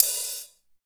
87 OP HAT 2.wav